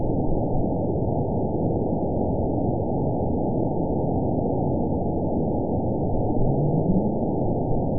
event 920808 date 04/09/24 time 20:51:07 GMT (1 year, 1 month ago) score 9.26 location TSS-AB03 detected by nrw target species NRW annotations +NRW Spectrogram: Frequency (kHz) vs. Time (s) audio not available .wav